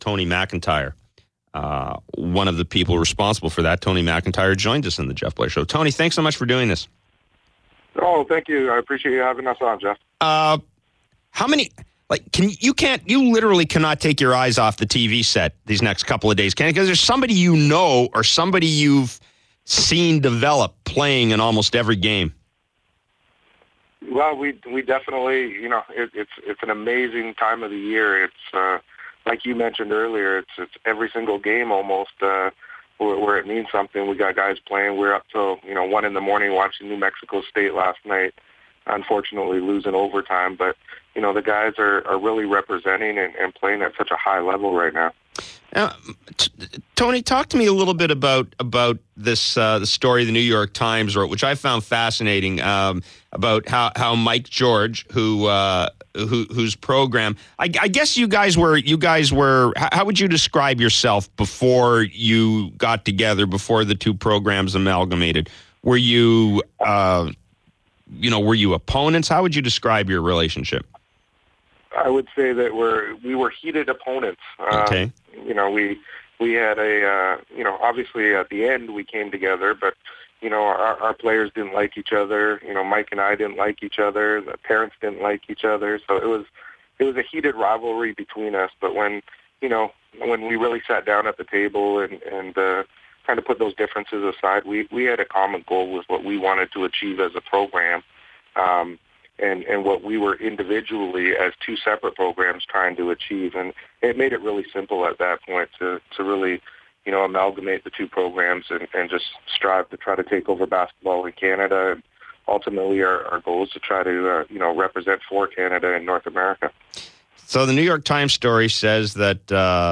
INTERVIEW on FAN590